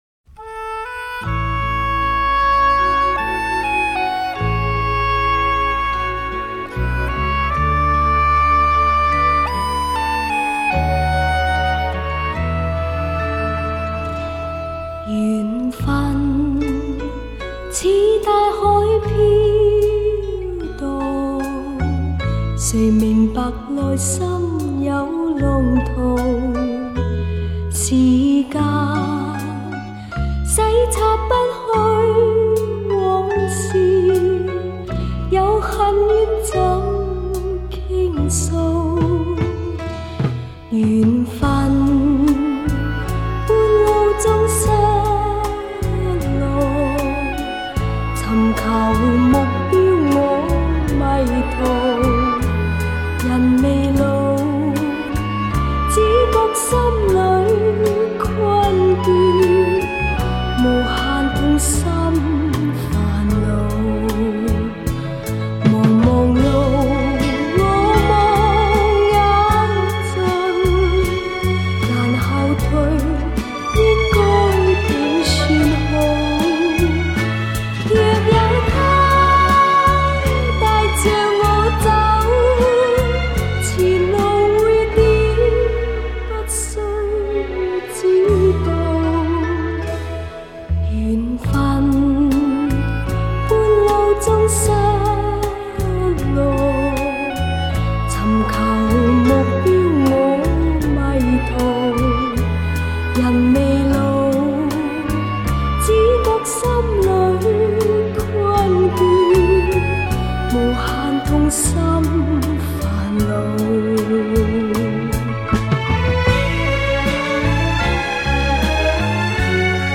香港电台广播剧主题曲